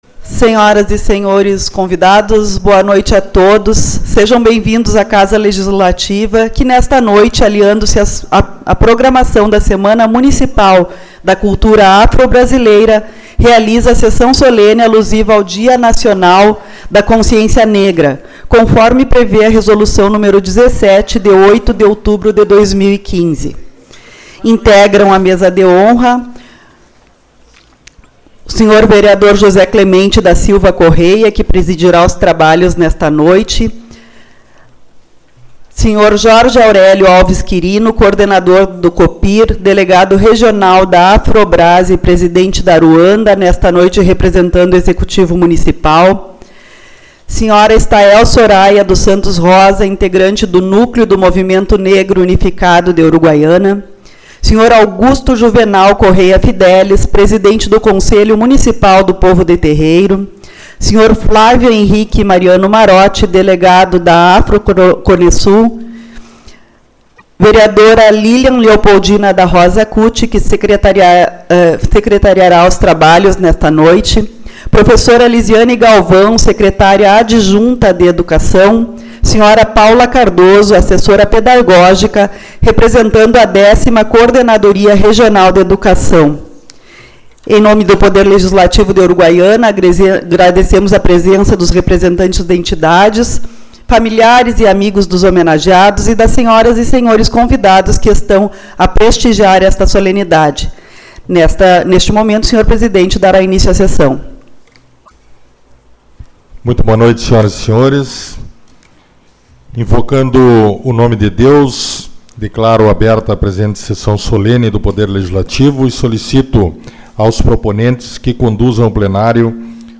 19/11 - Sessão Solene-Semana da Cultura Afro